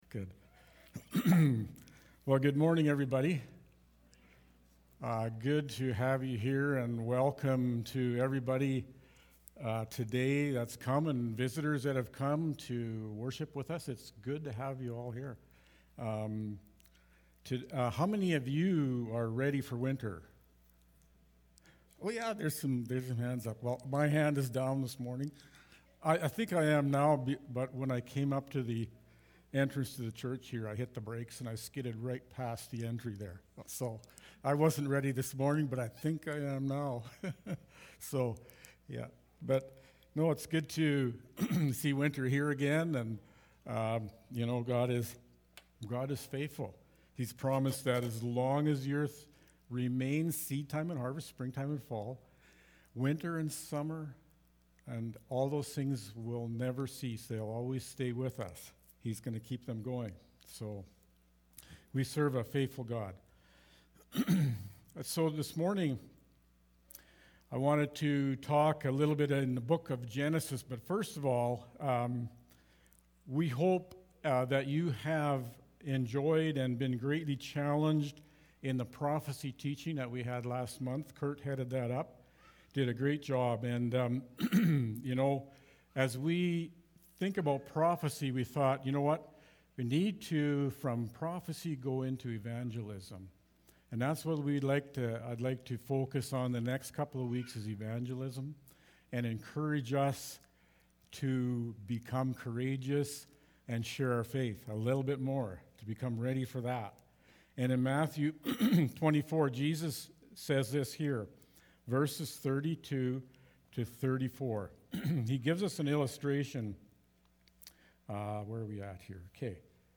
November-7-2021-sermon-audio.mp3